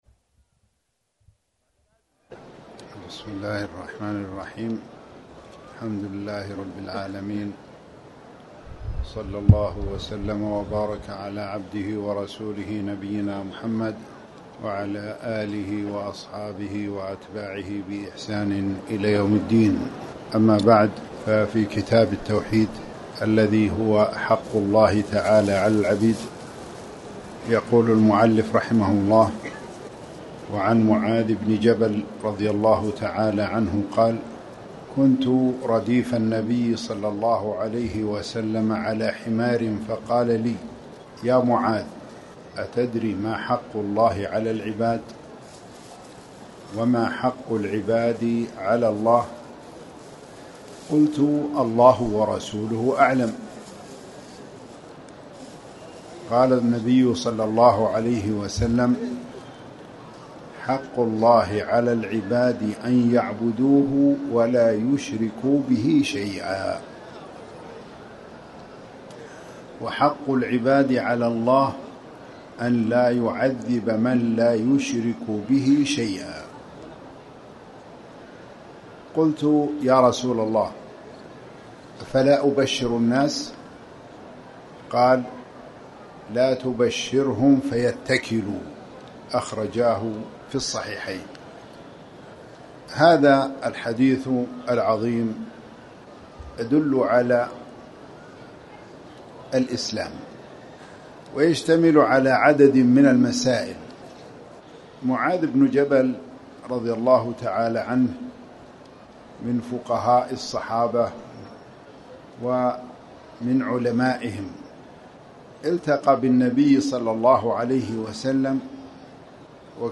تاريخ النشر ٢ صفر ١٤٣٩ هـ المكان: المسجد الحرام الشيخ